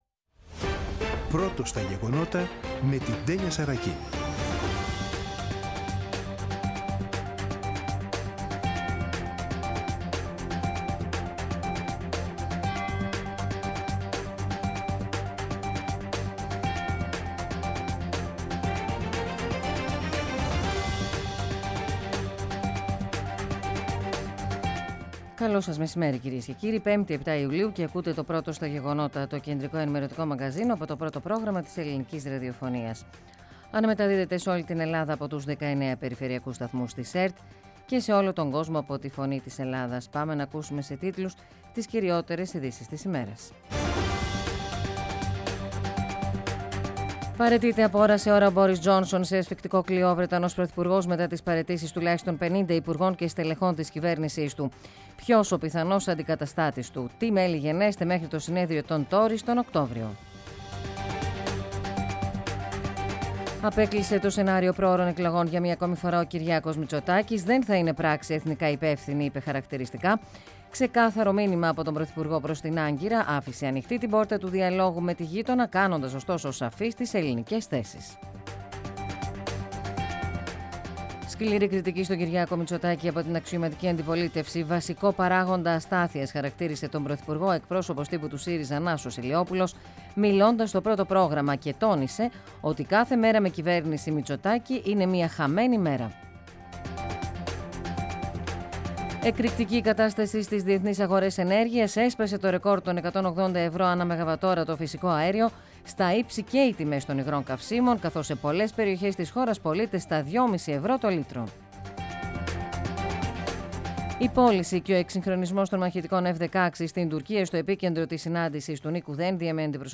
Το κεντρικό ενημερωτικό μαγκαζίνο του Α΄ Προγράμματος , από Δευτέρα έως Παρασκευή στις 14.00. Με το μεγαλύτερο δίκτυο ανταποκριτών σε όλη τη χώρα, αναλυτικά ρεπορτάζ και συνεντεύξεις επικαιρότητας.